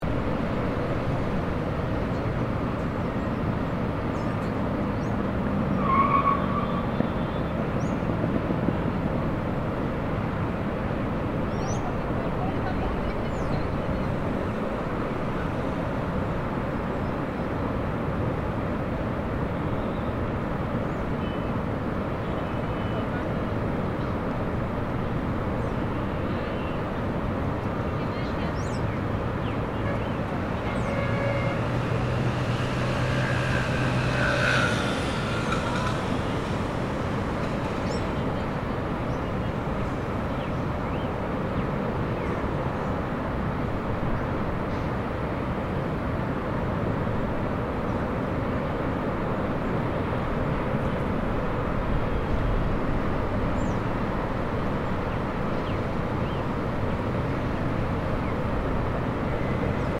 Traffic noise in Medellin
Noise, the soundtrack of our everyday life in a city. If you look at the spectogram of the recording you'll find it very boring and static, almost like a pink noise.
The recording was made in Pueblito Paisa, Medellín, Colombia on 21 december 2023 at around 1 p.m. with a Zoom H1N.